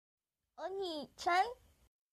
Anime
Anime Girl Saying Onii Chan